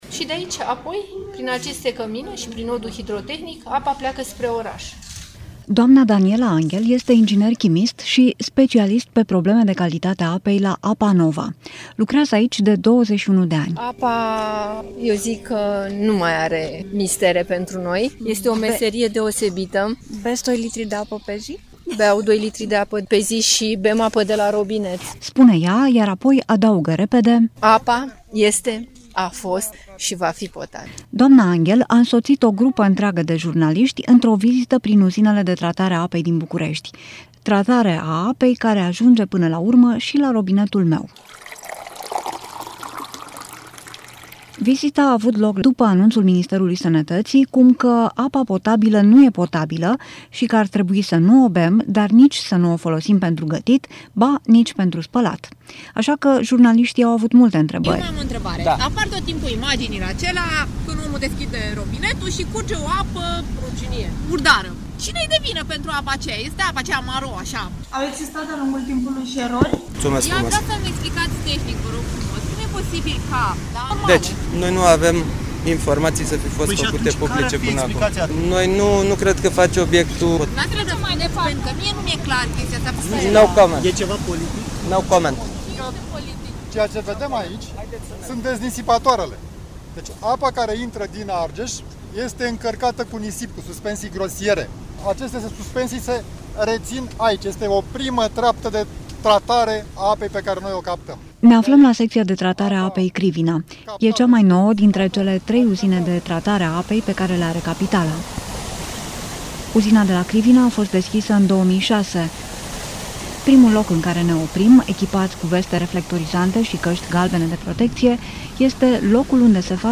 Drumul Apei. Din Dâmboviţa, la robinetul bucureştenilor – Reportaj